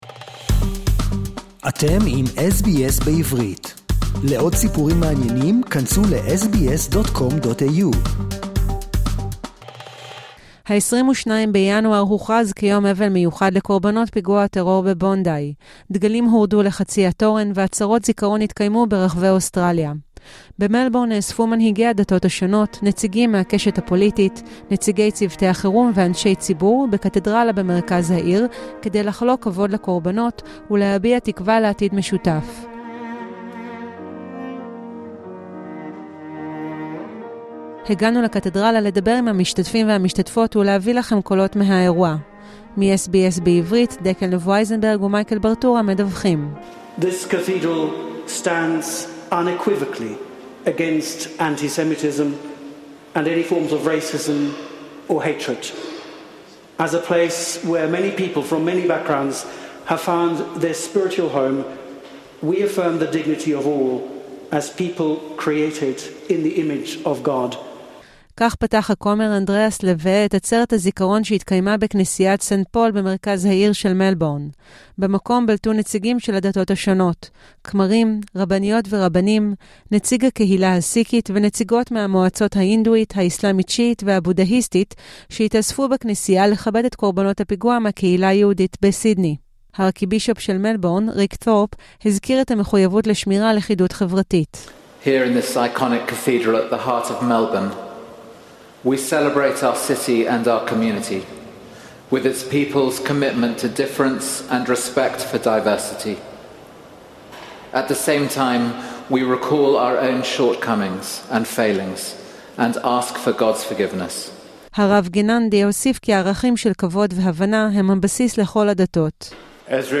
ה-22 בינואר הוכרז כיום אבל מיוחד לקורבנות פיגוע הטרור בבונדאי וברחבי אוסטרליה קוימו עצרות זיכרון והדגלים הורדו לחצי התורן. הגענו לעצרת במלבורן בקתדרלת סנט פול במרכז העיר להביא לכם את הקולות מהאירוע.